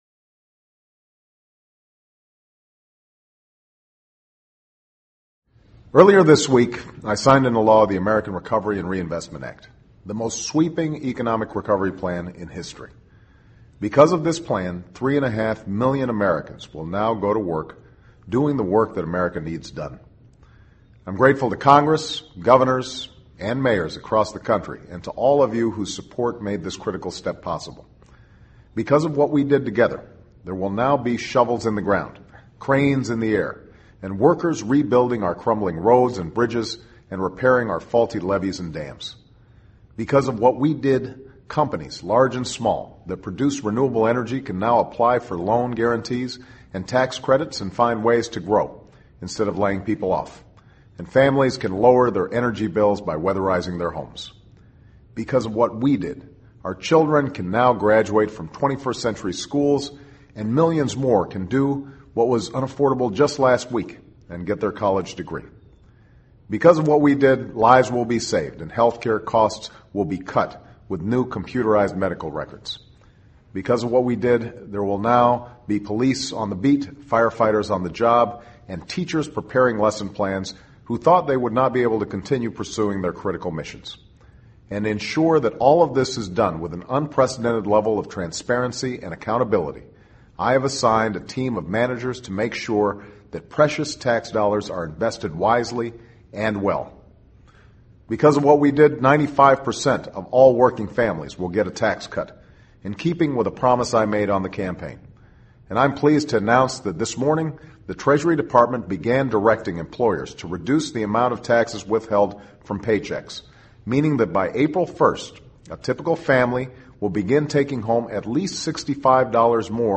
【美国总统电台演说】2009-02-21 听力文件下载—在线英语听力室